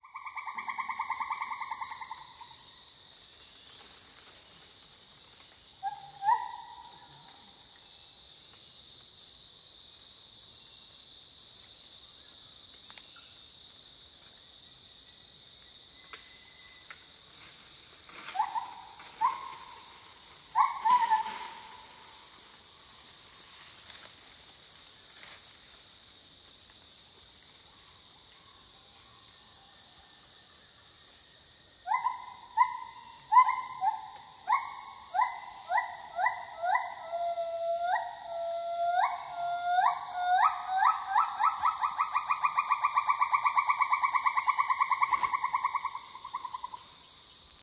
Hylobates funereus
Category: Songs
Northern-grey-gibbon.m4a